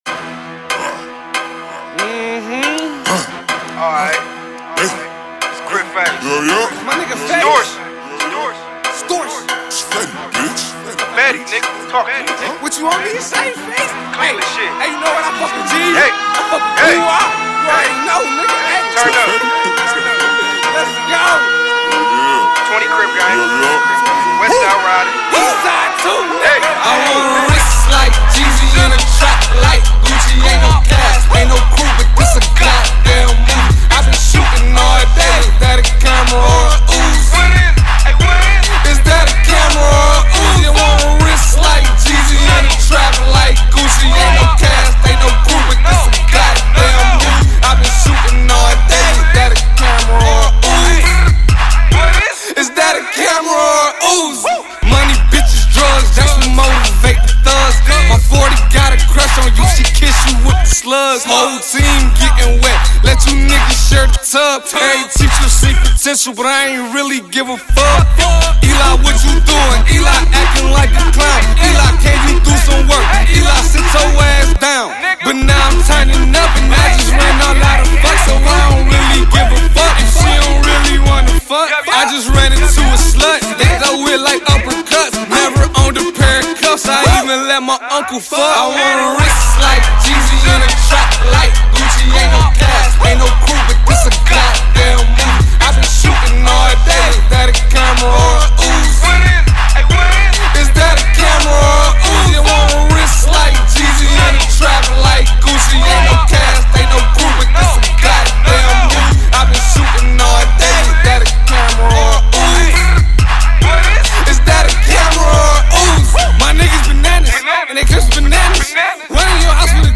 Hiphop
Trill / Turned Up /Trap